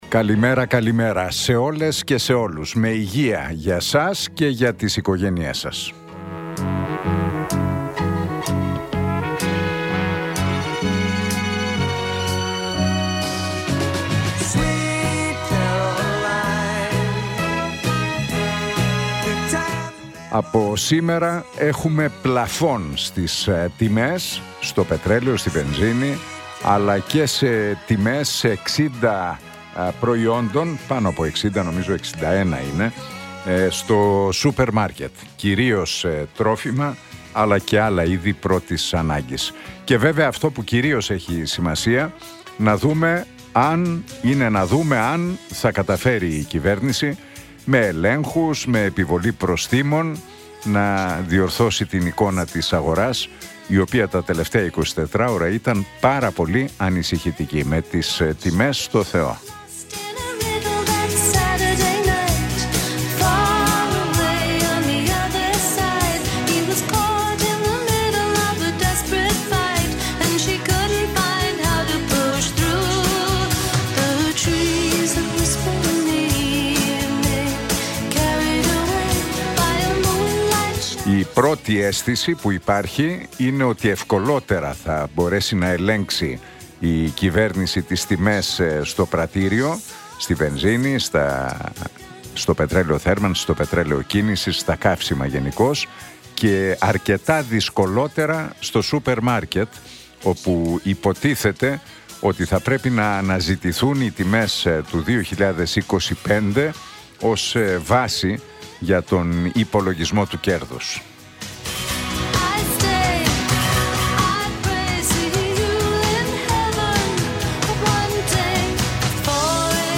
Ακούστε το σχόλιο του Νίκου Χατζηνικολάου στον ραδιοφωνικό σταθμό Realfm 97,8, την Πέμπτη 12 Μαρτίου 2026.